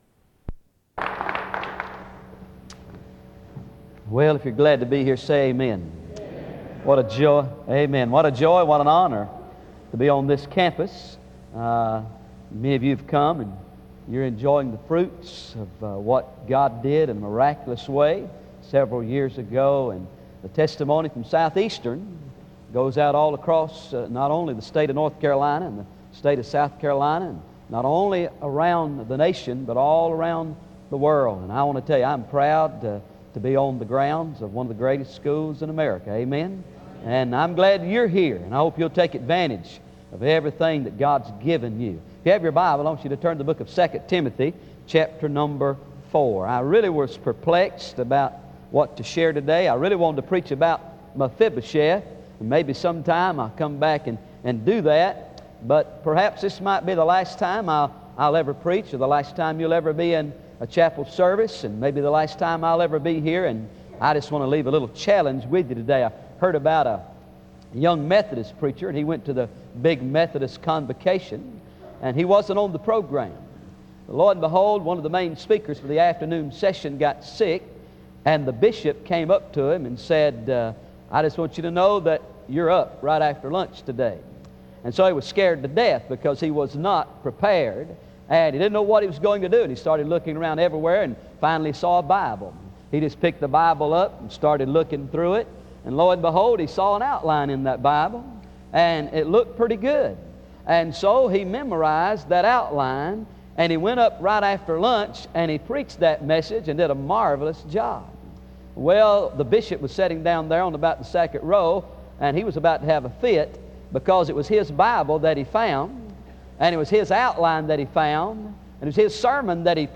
In Collection: SEBTS Chapel and Special Event Recordings - 2000s